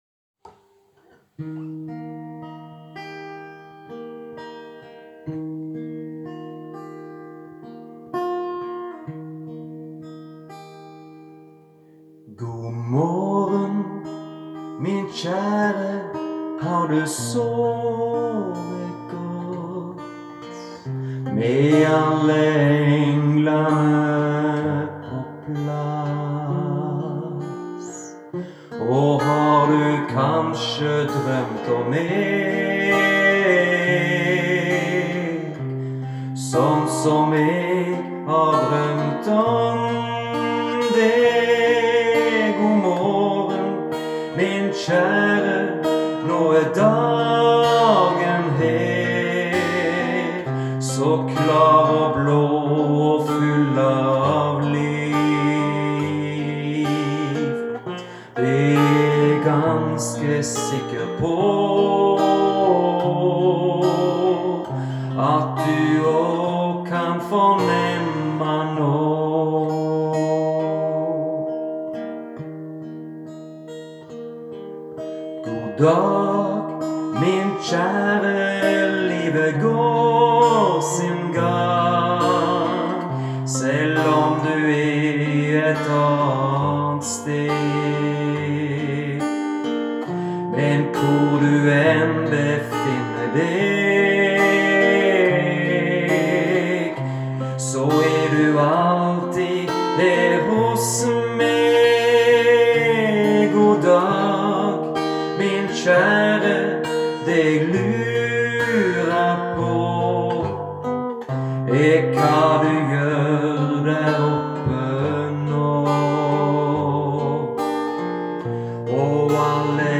Jeg spiller gitar og piano.